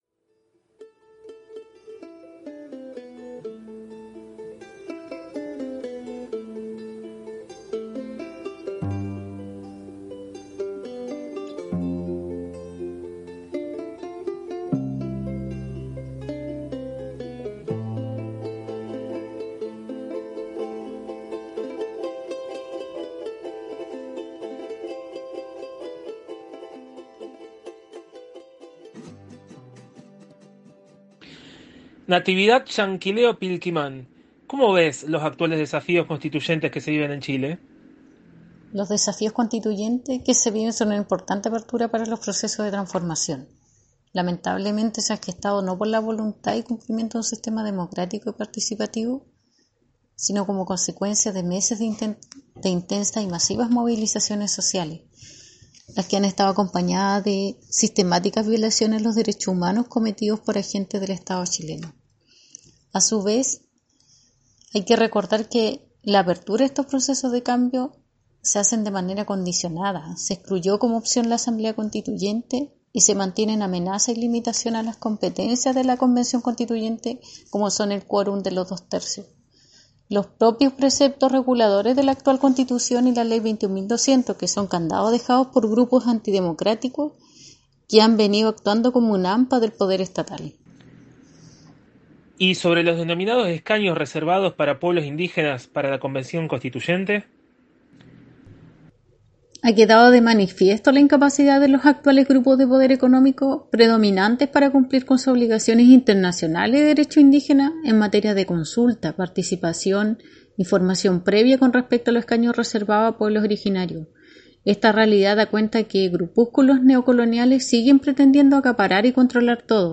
A continuación, entrevista realizada por: